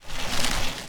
bikemove.ogg